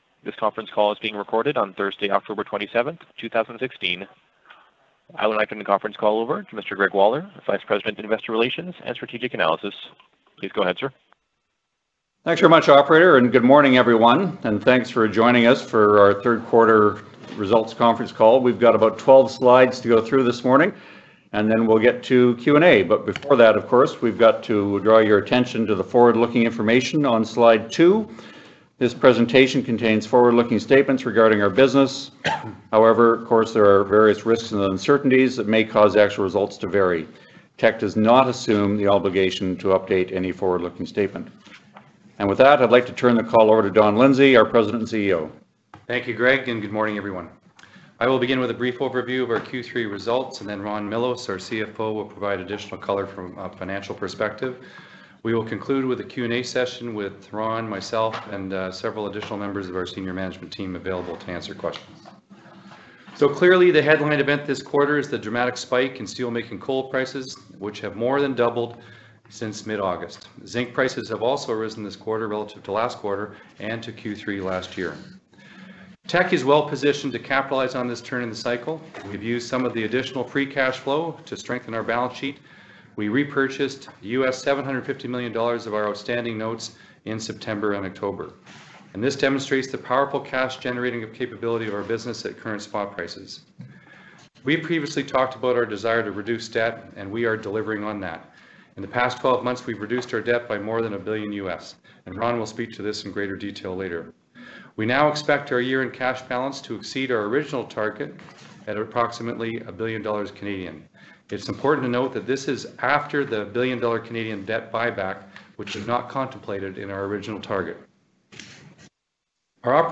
Q3 2016 Financial Report [PDF - 1.10 MB] Q3 2016 Financial Report Presentation Slides [PDF - 1.71 MB] Q3 2016 Quarterly Report Conference Call Audio [MP3 - 49.17 MB] Q3 2016 Quarterly Report Conference Call Transcript [PDF - 0.20 MB]